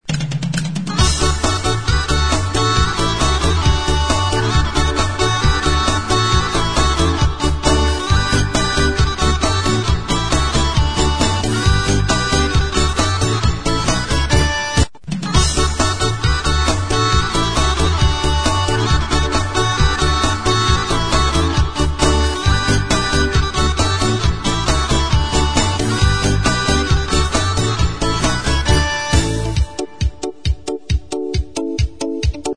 (Включается любая народная музыка.